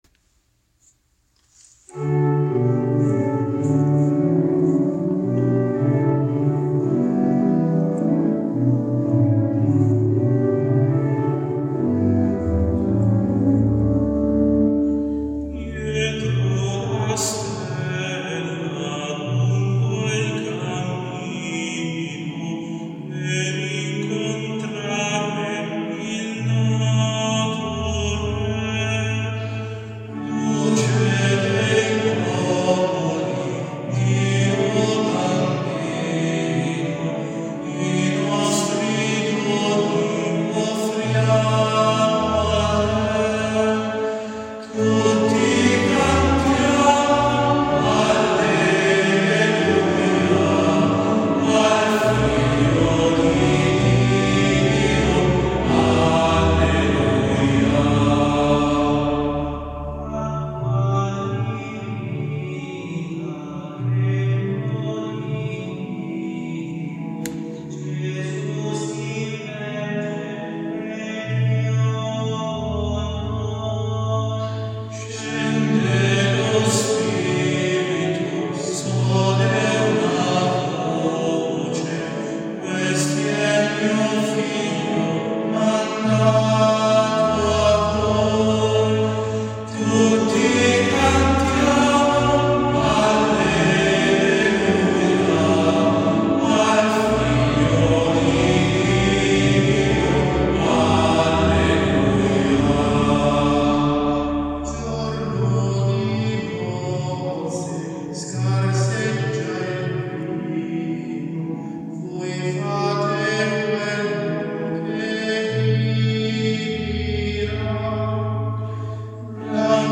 Per questa ragione abbiamo riservato al solista la  proposta e a un gruppo di cantori la ripetizione (prime otto battute), in modo che si possa percepire un effetto p-mf.
La frase finale, coerentemente con il testo, chiede il coinvolgimento di tutta l’assemblea, che si unisce con gioia nel cantare Gesù, luce del Padre.